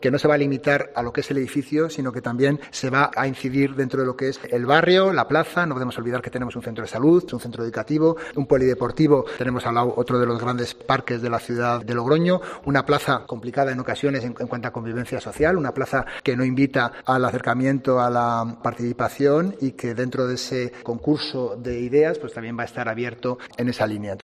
Rubén Antoñanzas, concejal del PR